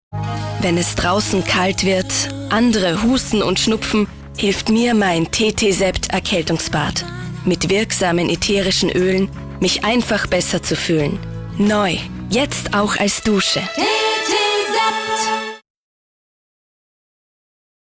Sprecherdemos
02 Tetesept Werbung.mp3